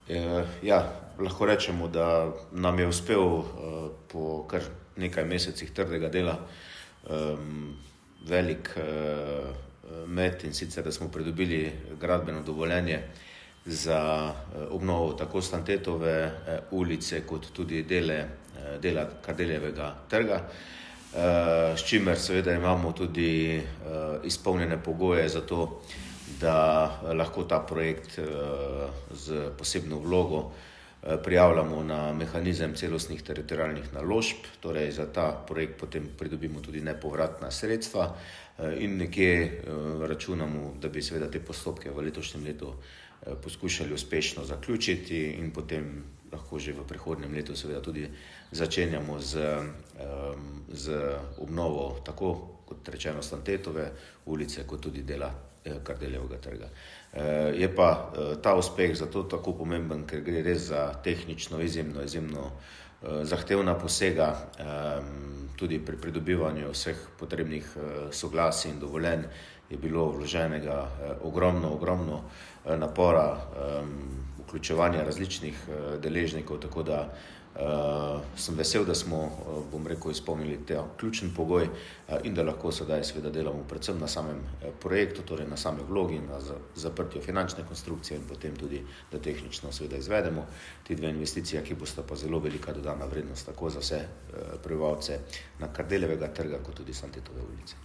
Tonska izjava župana Mestne občine Velenje Petra Dermola